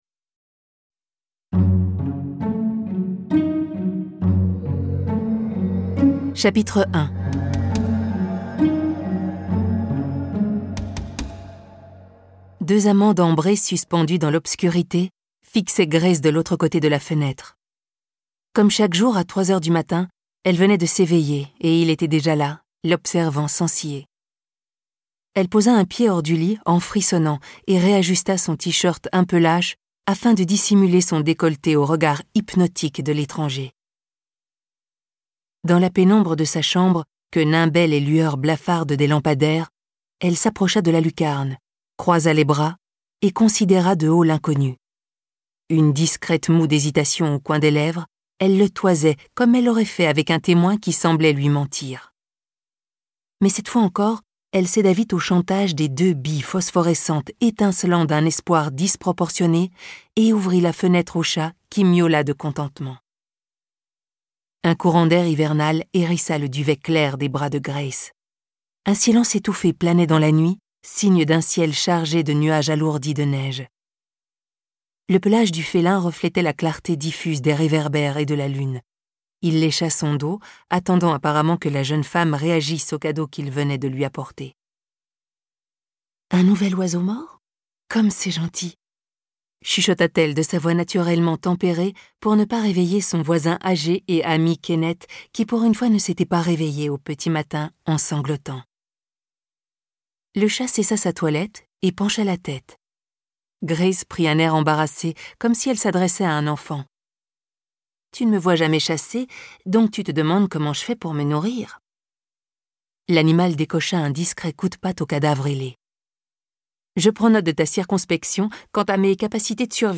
Télécharger le fichier Extrait MP3